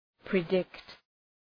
Προφορά
{prı’dıkt}